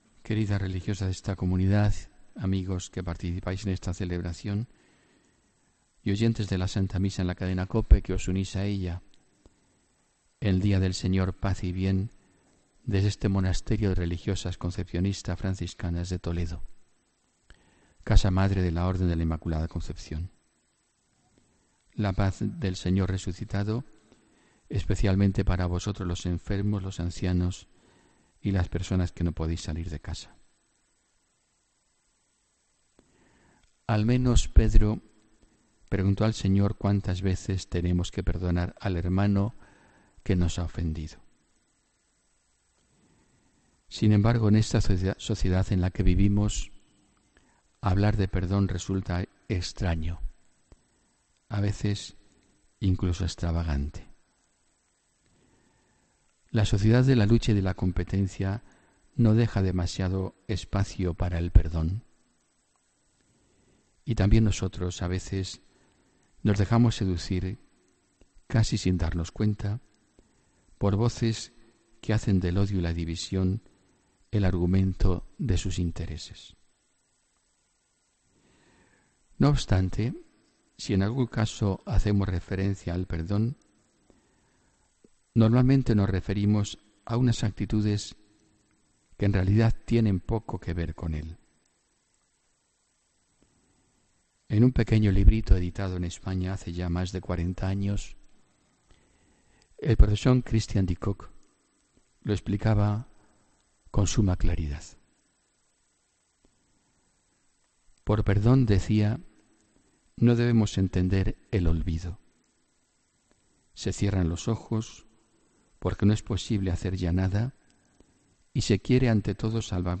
Homilía 17 de septiembre de 2017